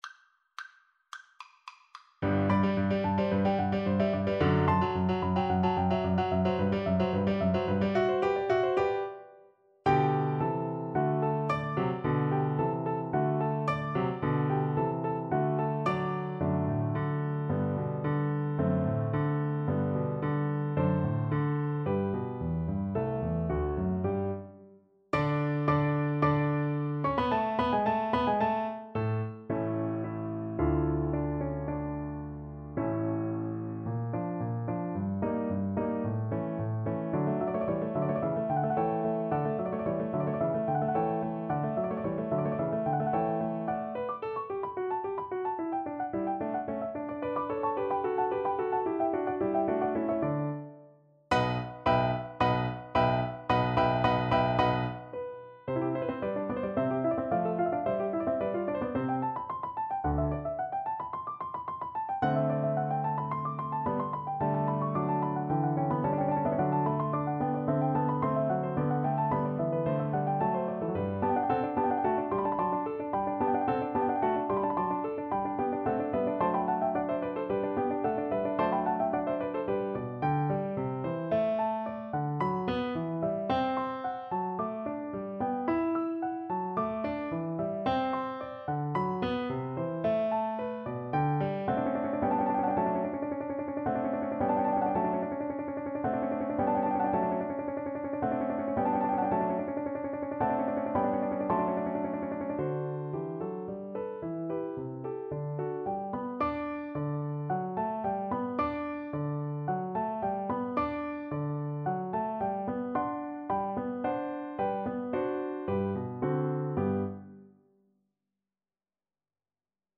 Allegro assai =220 (View more music marked Allegro)
Classical (View more Classical Trombone Music)